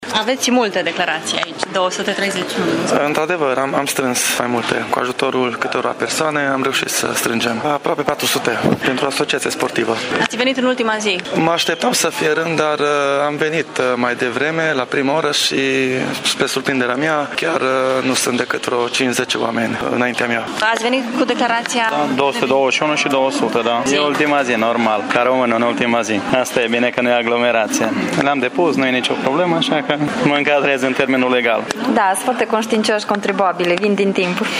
La cele 4 ghișee deschise la sediul Finanțelor erau doar câțiva oameni în această dimineață: